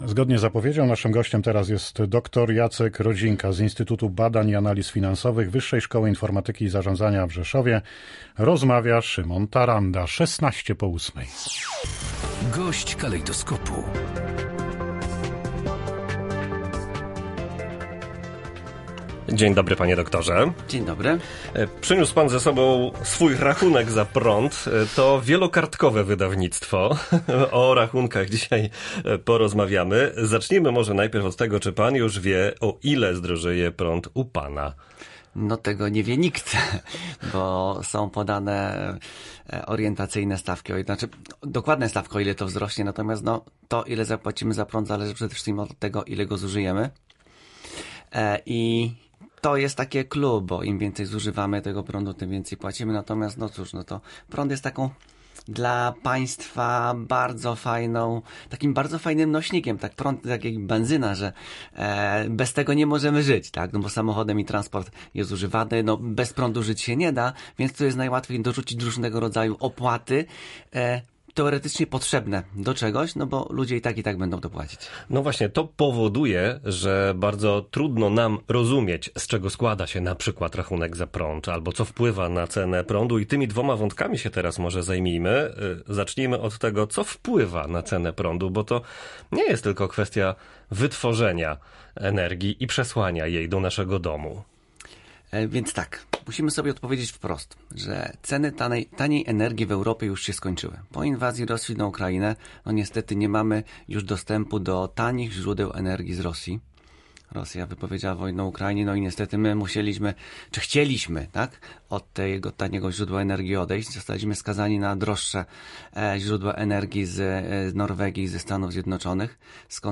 GOŚĆ DNIA.